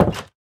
Minecraft Version Minecraft Version latest Latest Release | Latest Snapshot latest / assets / minecraft / sounds / block / wooden_door / close2.ogg Compare With Compare With Latest Release | Latest Snapshot